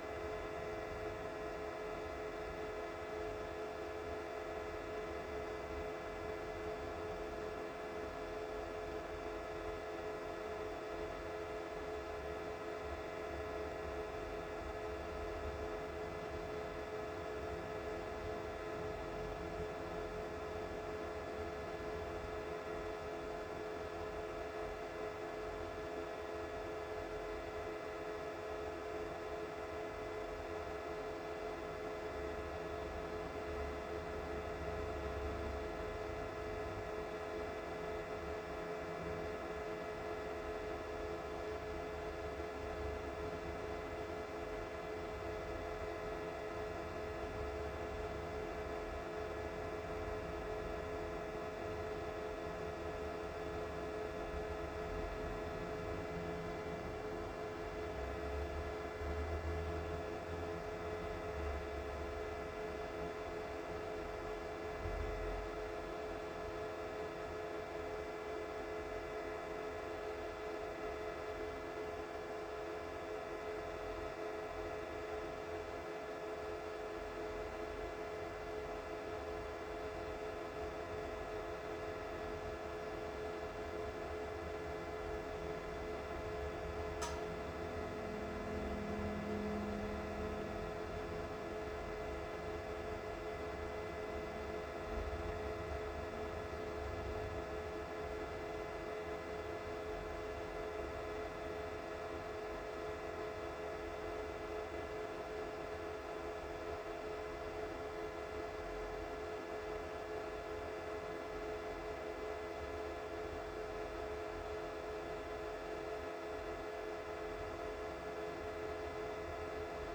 Server-noise.m4a